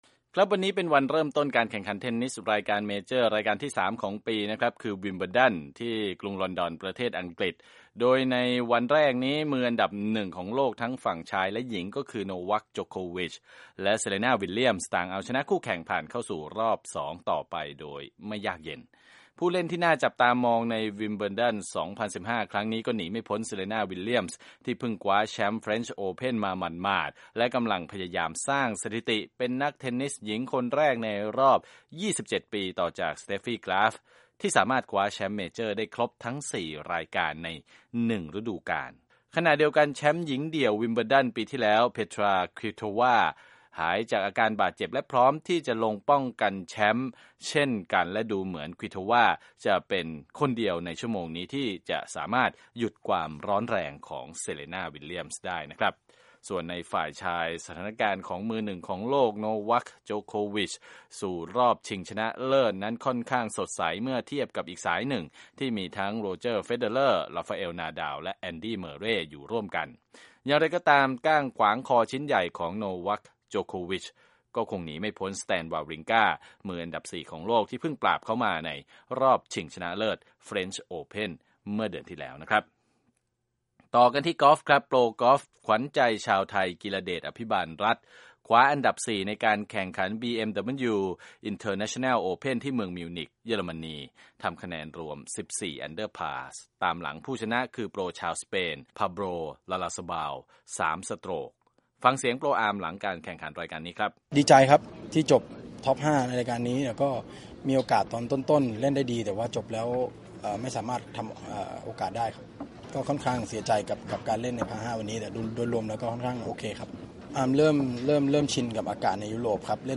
ข่าวกีฬา: เทนนิสวิมเบิลดันเริ่มแล้ววันนี้